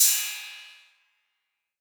808CY_2_Orig_ST.wav